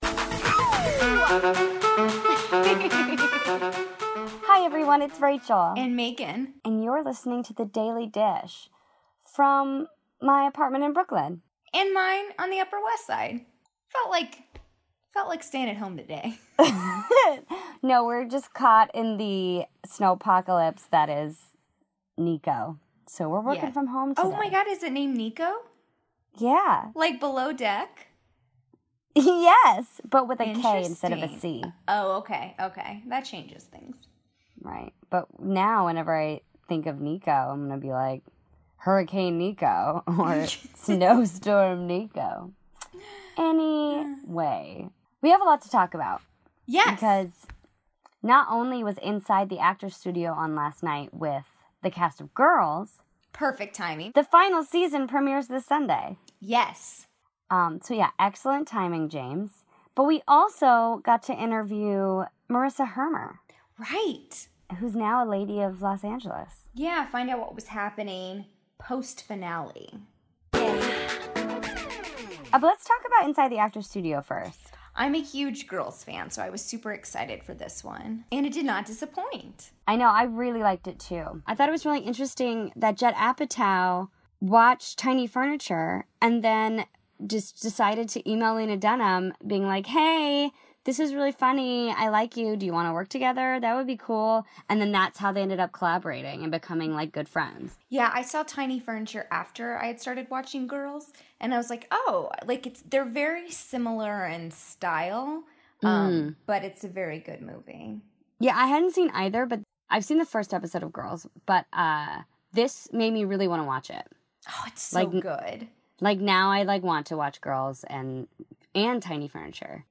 2.10.17 - Girls, Ladies, and Babyccinos (Our Interview with Marissa Hermer)
The cast of Girls was on Inside the Actors Studio, and we're breaking down the best moments that didn't make it to TV. Then, we sat down with Marissa Hermer.